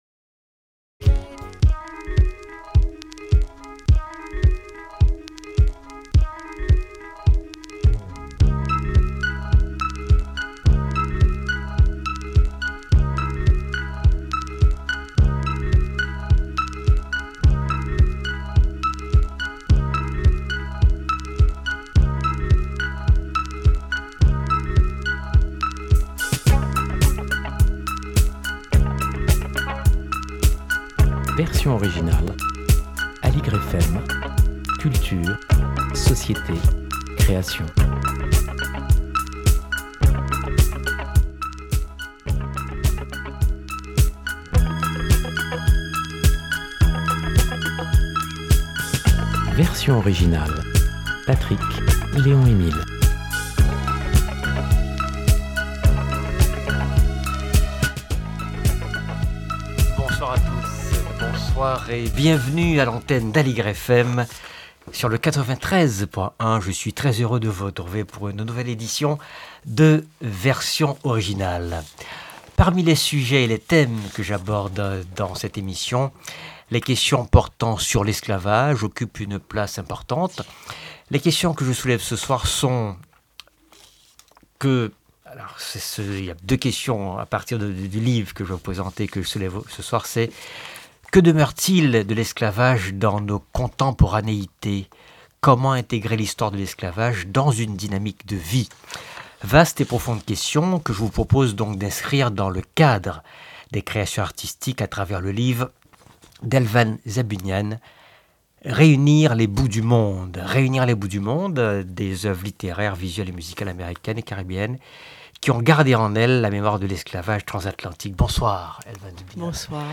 Invitée : l'historienne de l'art